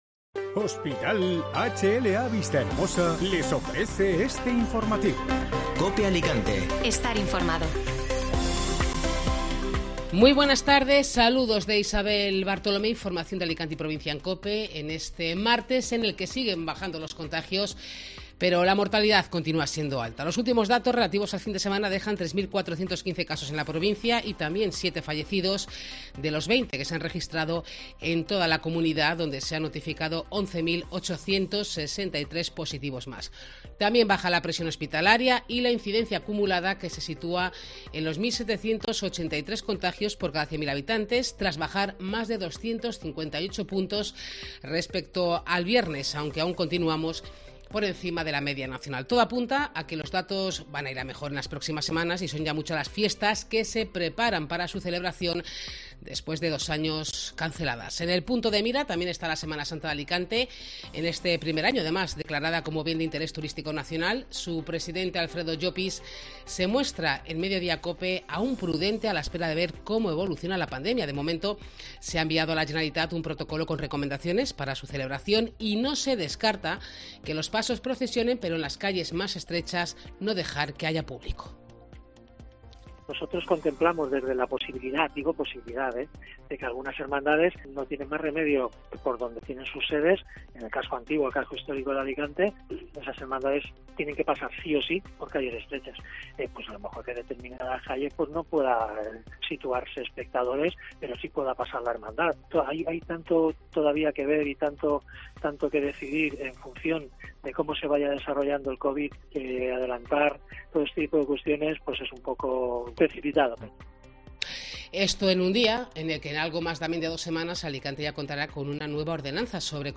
Último boletín